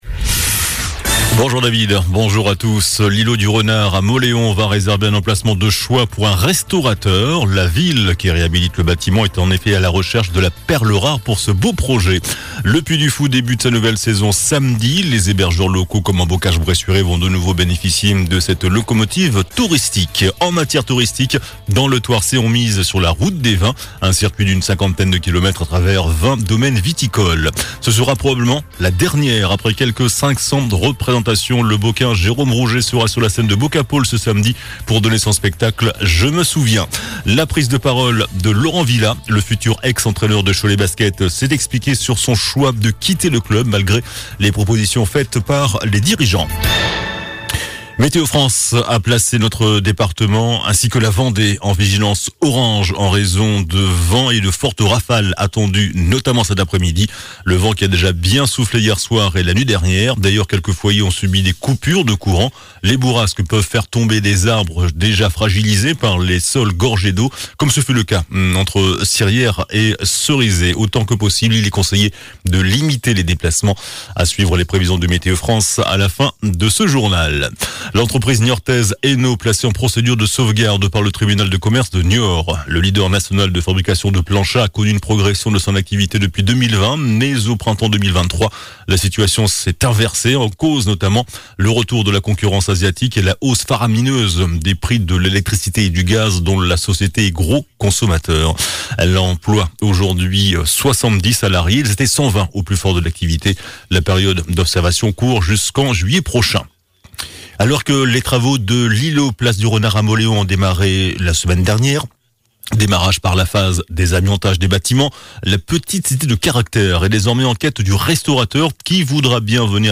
JOURNAL DU JEUDI 28 MARS ( MIDI )